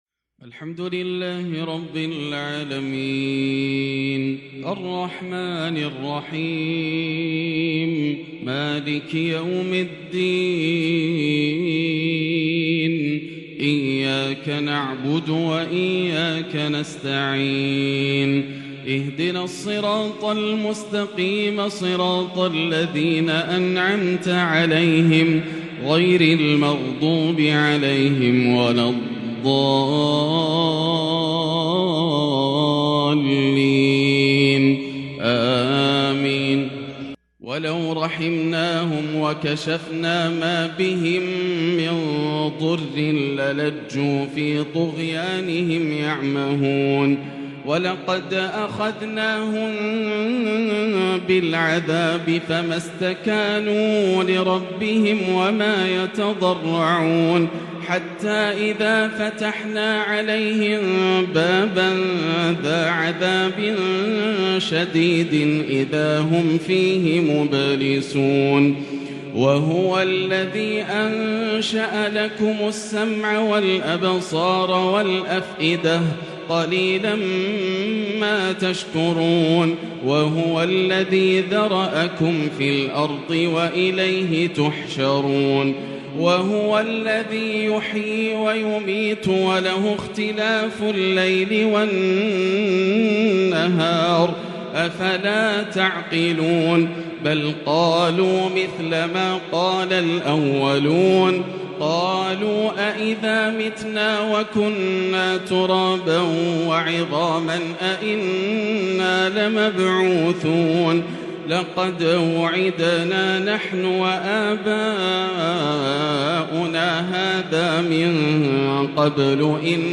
“تلفح وجوههم النار” الآسر د.ياسر الدوسري وكفى به محبراً متغنيّا بكتاب ربه .. أداء جديد بروح عجيبه > تلاوات عام 1443هـ > مزامير الفرقان > المزيد - تلاوات الحرمين